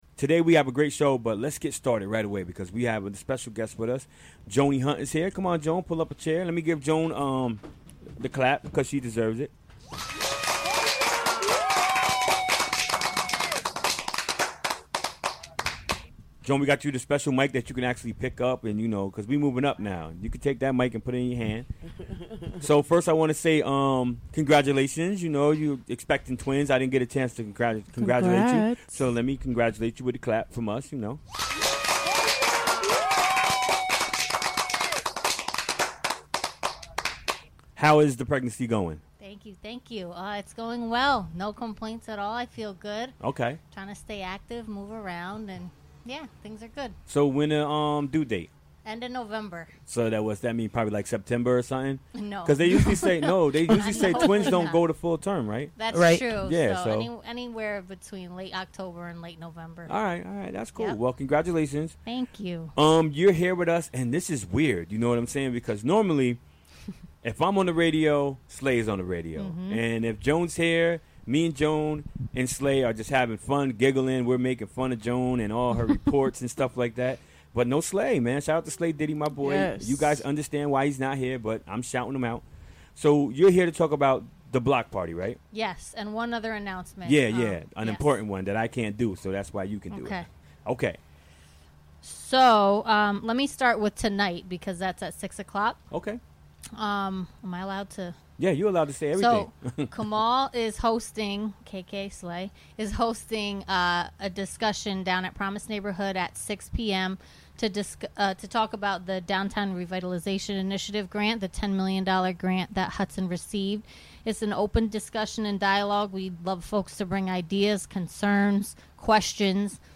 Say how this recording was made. Recorded during the Wed., Aug. 23, 2017, WGXC Afternoon Show.